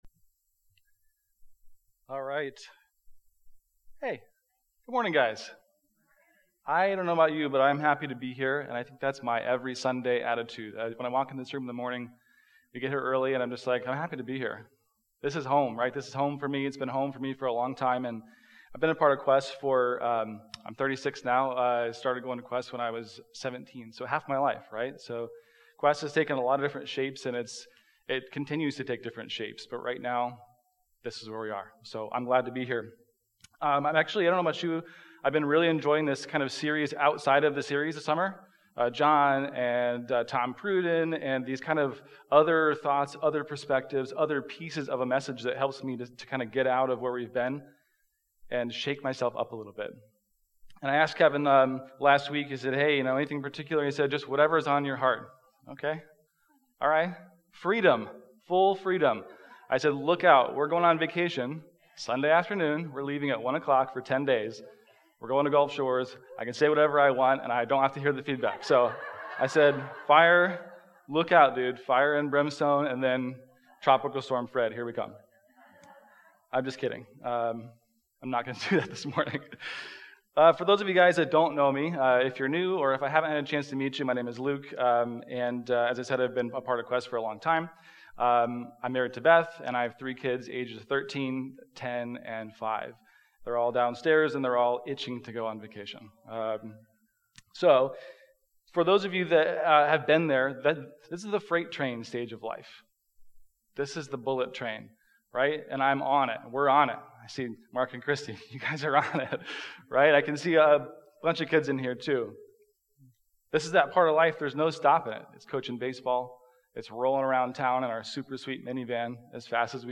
Join Quest for worship and a message on worry.